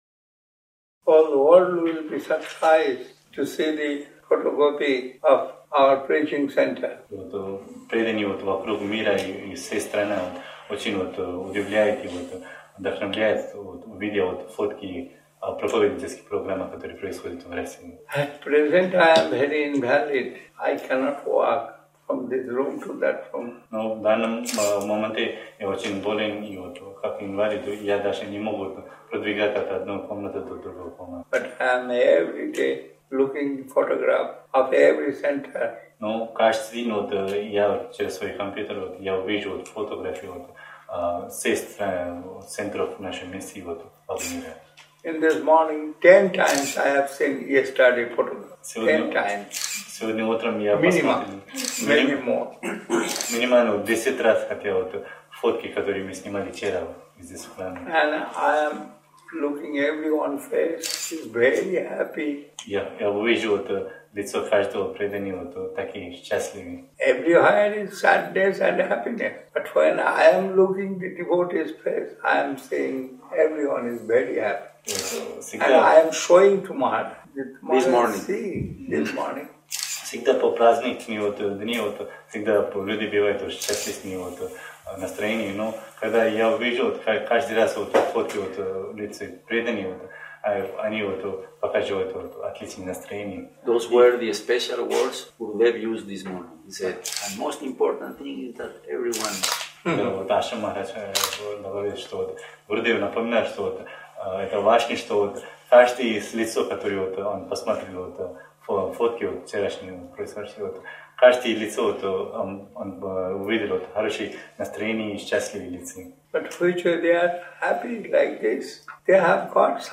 Место: Лахтинский Центр Бхакти йоги